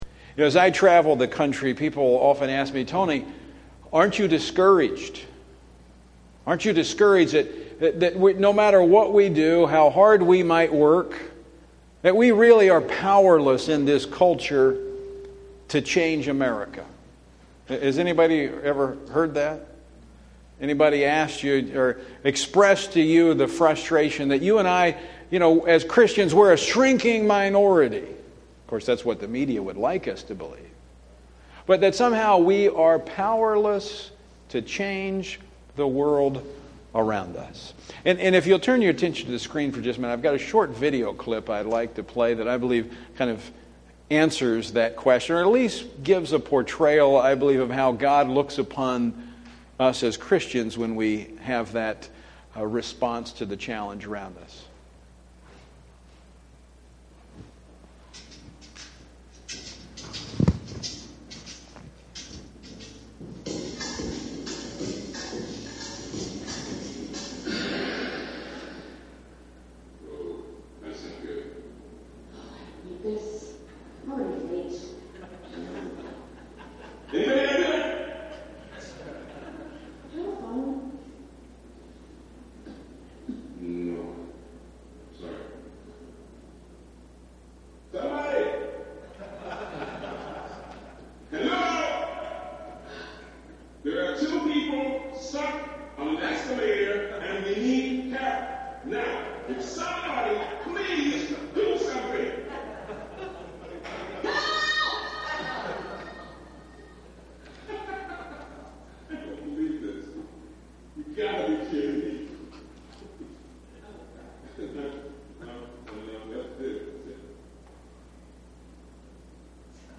guest speaker Tony Perkins from the Family Research Council